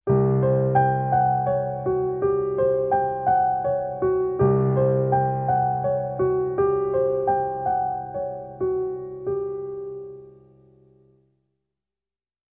Music Logo; Slow Horror Piano Melody.